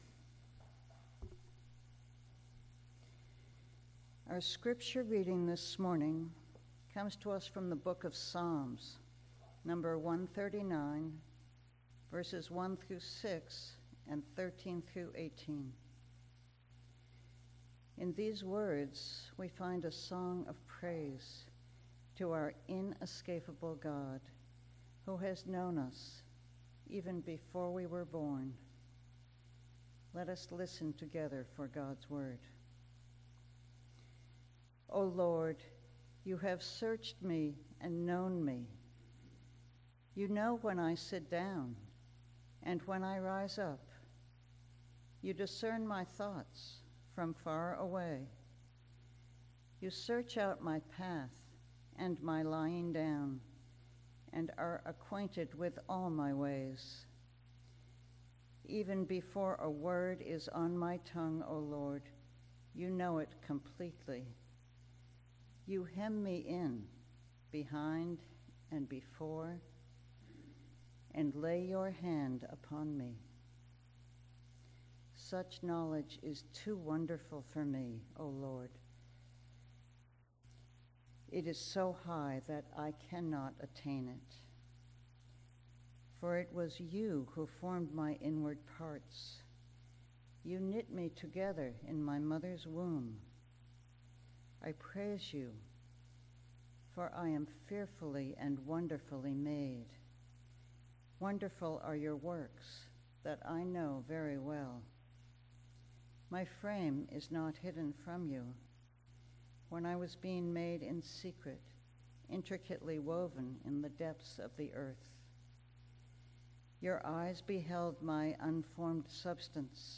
Message Delivered at: Charlotte Congregational Church (UCC)